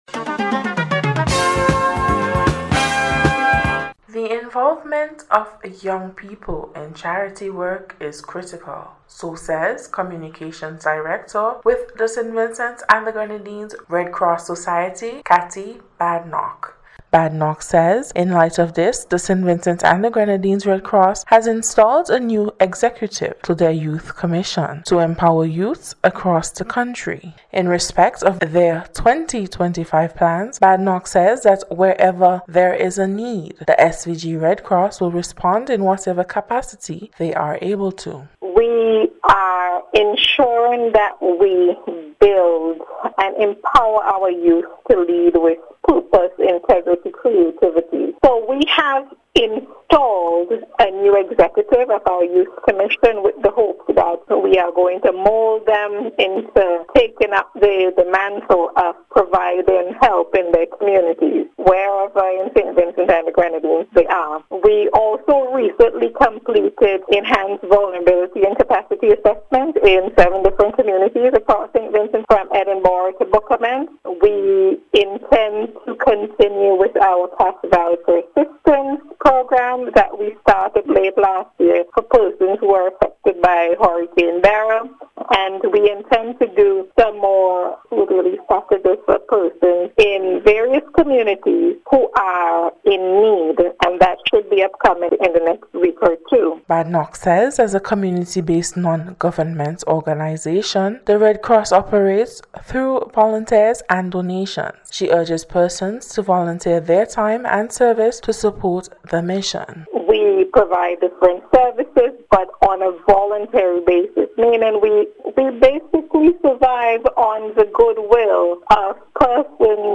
NBC’s Special Report- Thursday 9th January,2025